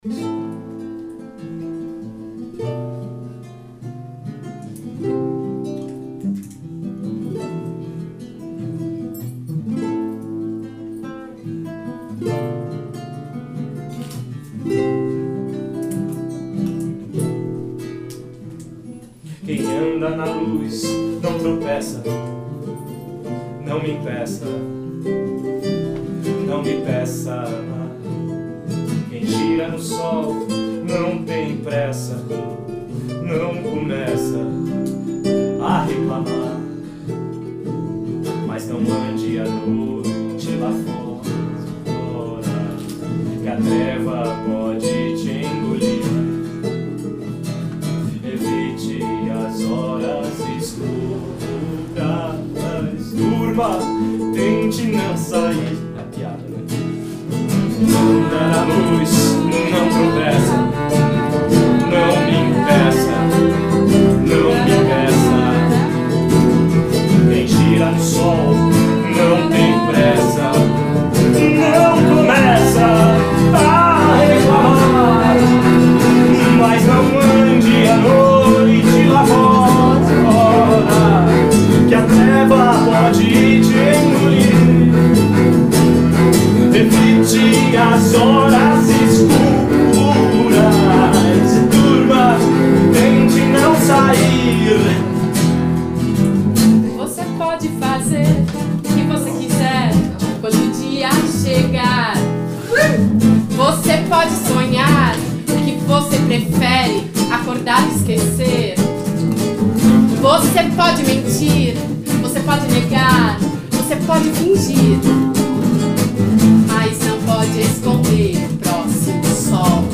Apresentação na Jambrohouse.